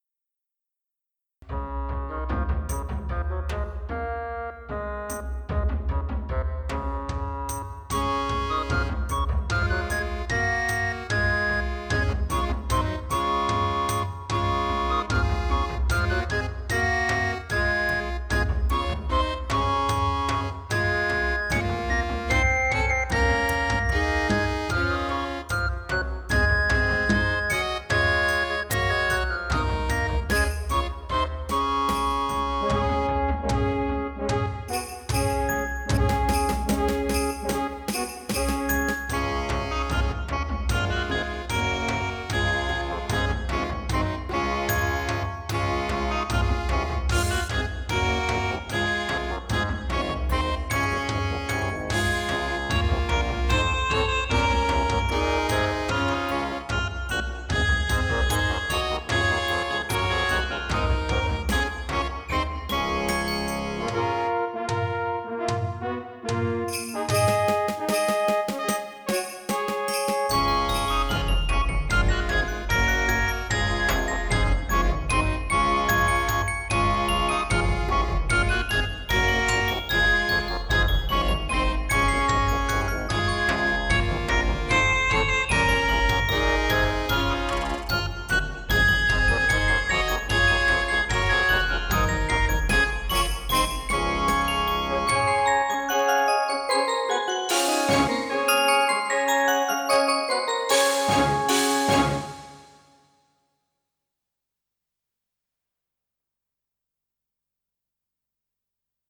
The arrangement below was created for a Clergy Conference in the early 1990s to demonstrate different ways in which classic hymnody could be tackled.